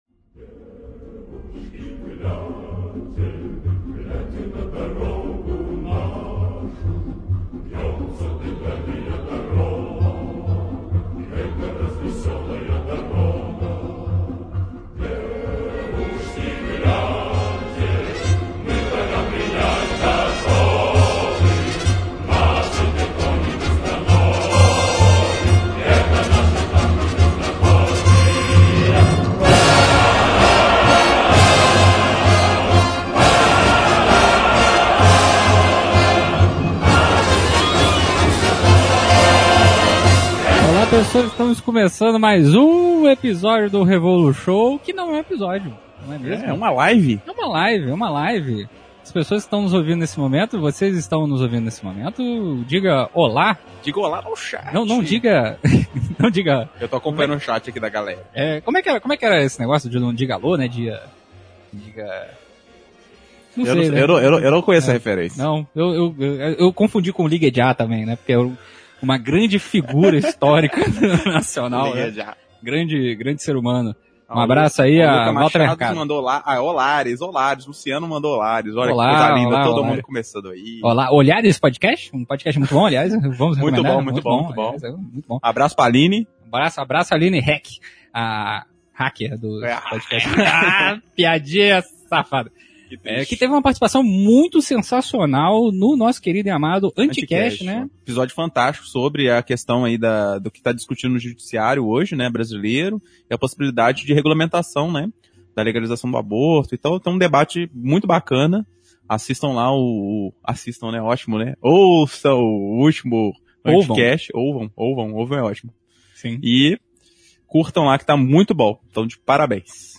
Live – Sorteio dos Cadernos Filosóficos de Lênin - Audecibel